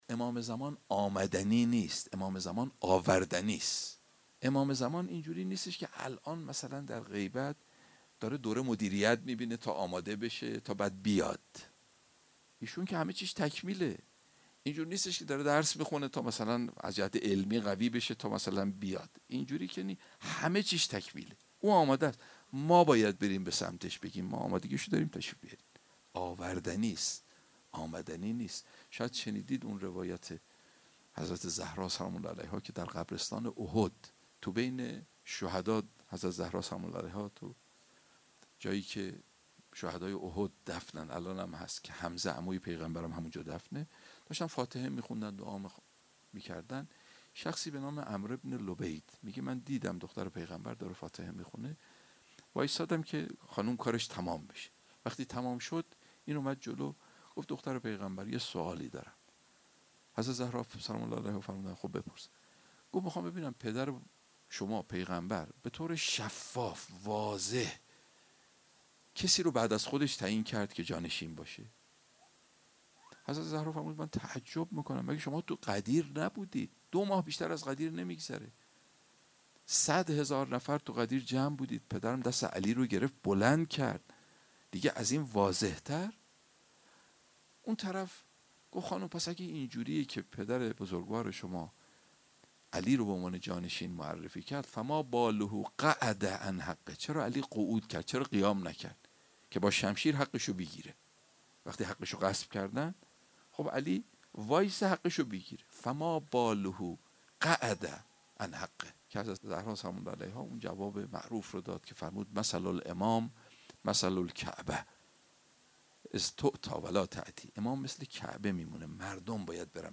در یکی از سخنرانی‌های خود بیان کرد که امام زمان(عج) آمدنی نیست، بلکه آوردنی است و ما باید مقدمات ظهورش را فراهم کنیم.